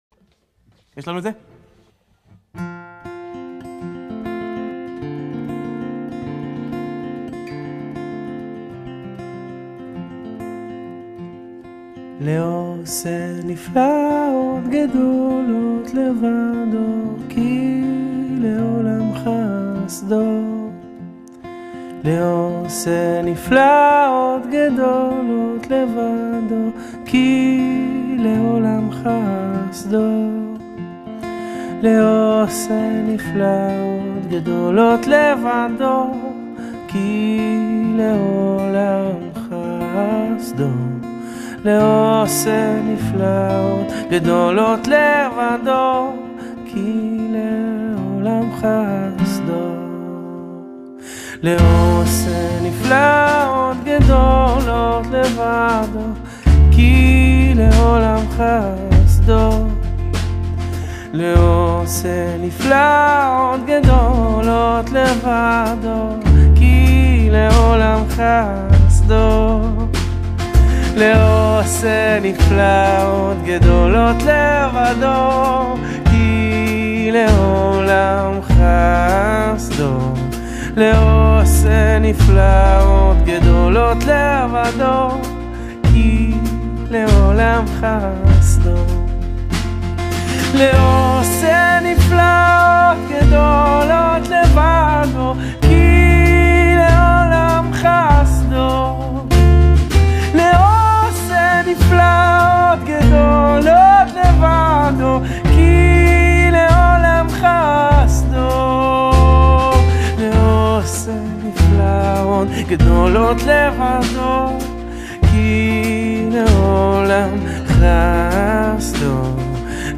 ברסלב חסידי